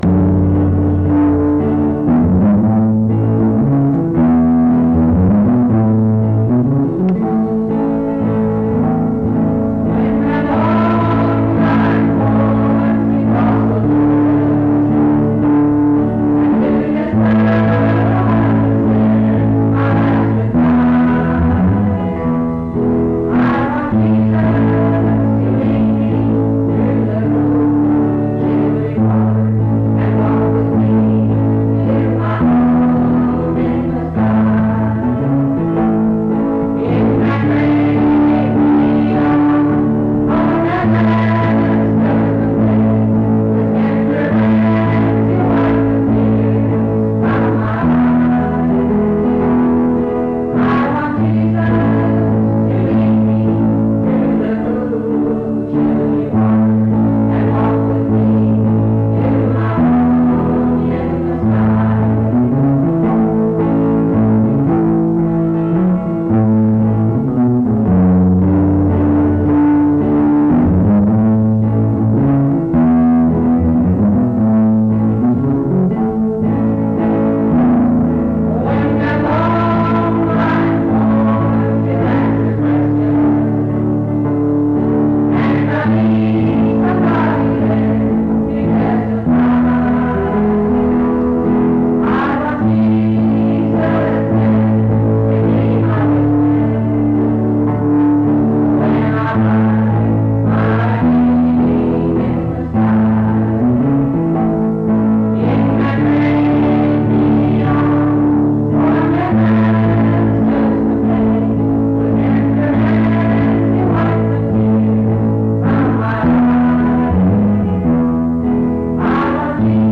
Mount Union Methodist Church II, rural, Monongalia County, WV, track 145K.